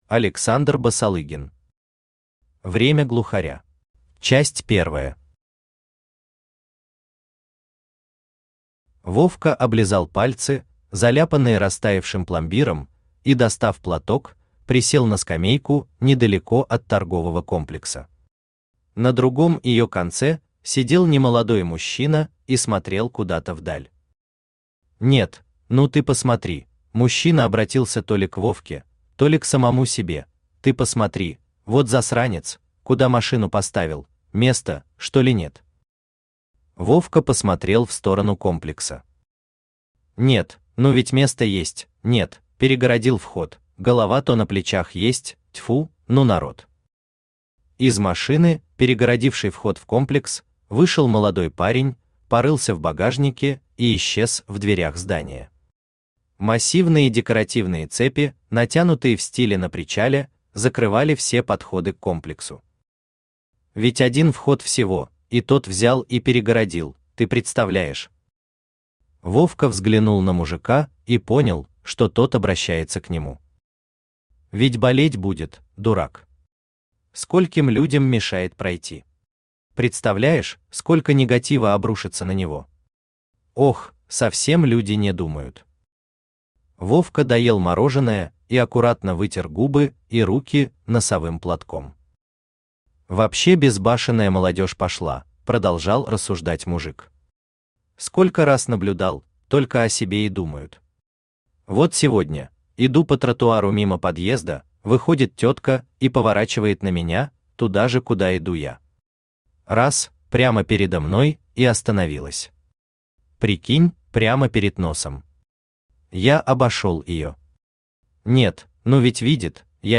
Аудиокнига Время Глухаря | Библиотека аудиокниг
Aудиокнига Время Глухаря Автор Александр Аркадьевич Басалыгин Читает аудиокнигу Авточтец ЛитРес.